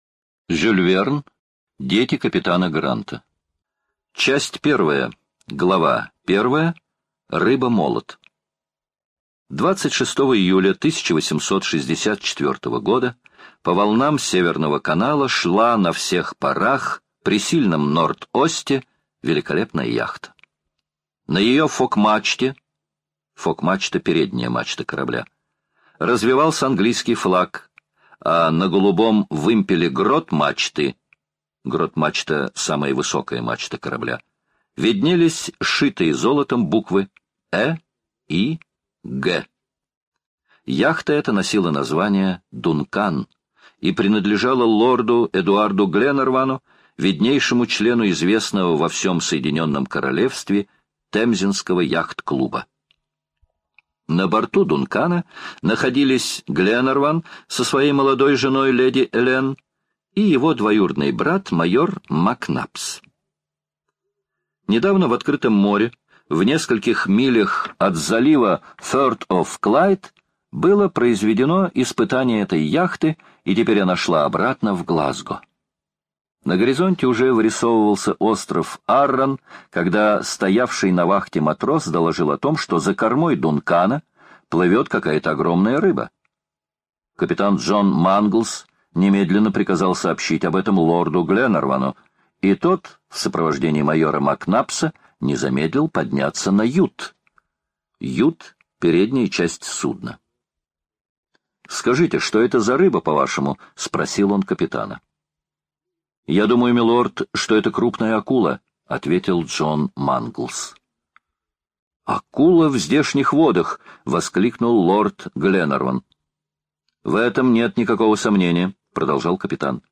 Дети капитана Гранта | слушать аудиокнигу онлайн по главам